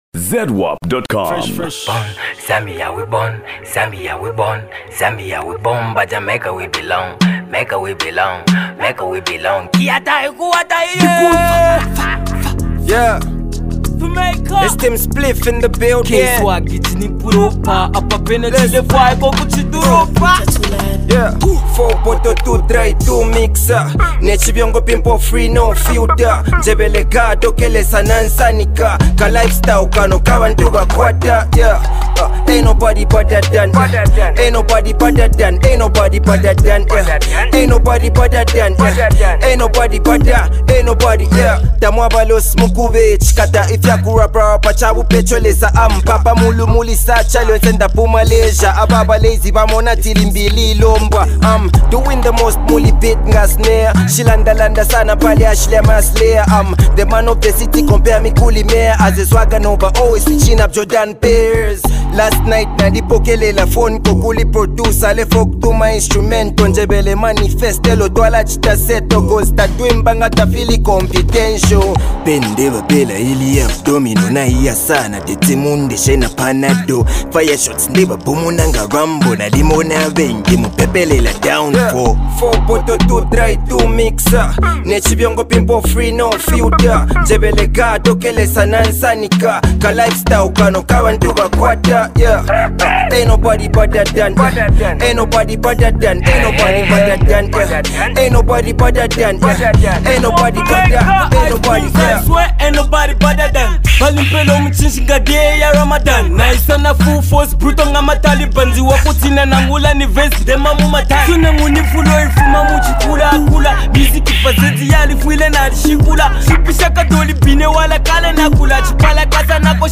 creative rapper